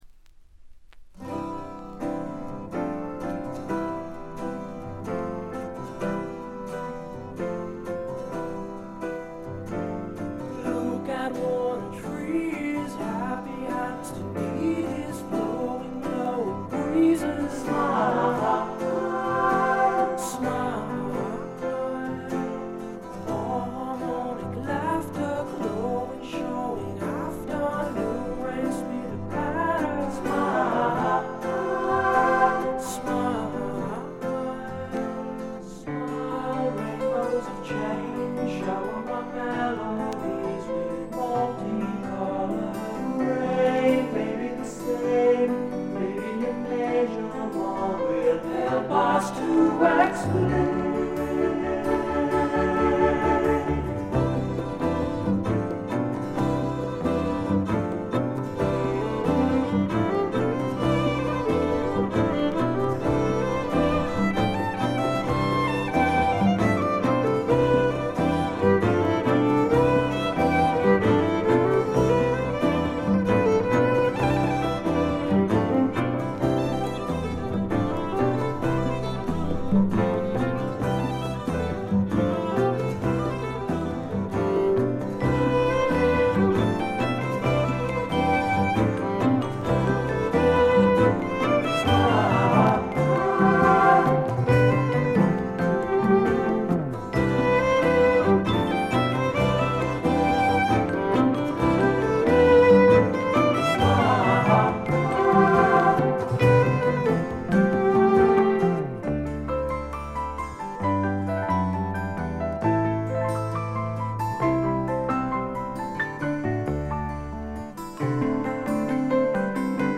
米国産クラシカル・プログレッシブ・フォークの名作です。
試聴曲は現品からの取り込み音源です。